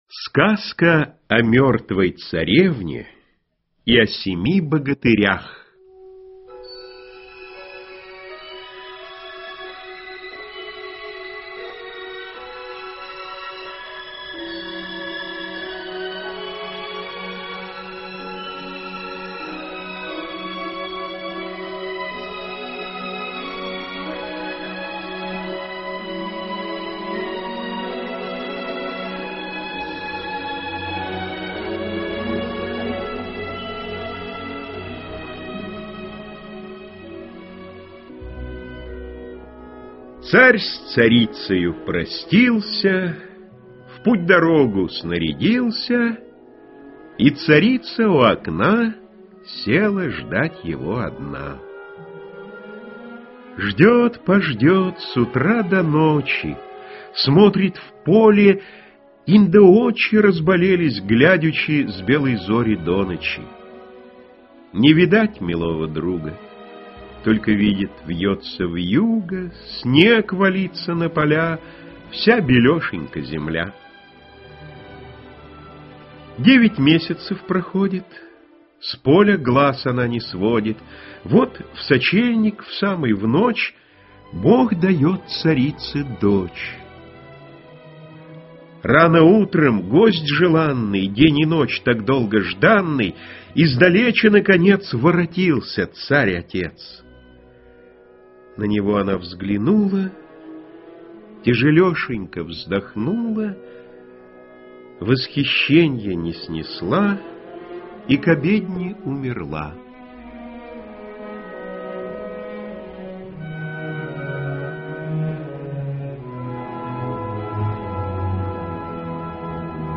Аудиосказка Сказка о мёртвой царевне и о семи богатырях для детей любого возраста в формате mp3 — слушать или скачать бесплатно и без регистрации.